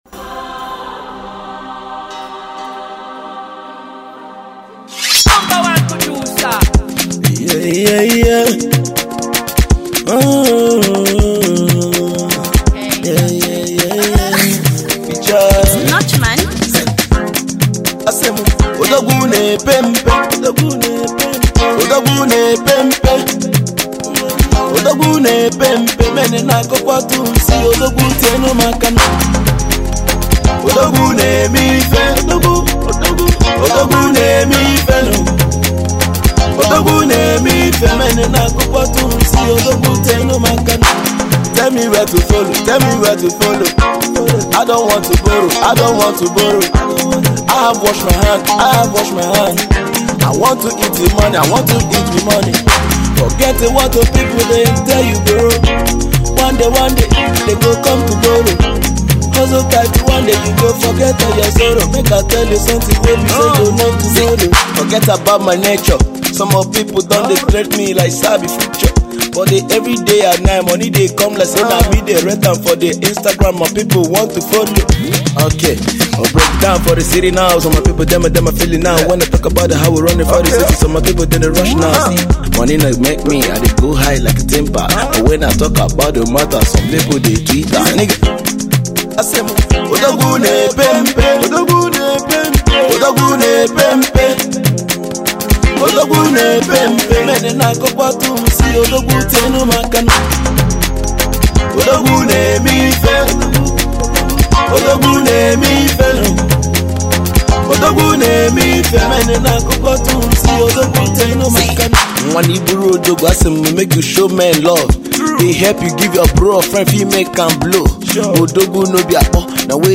One of the best free styler in “IMO State”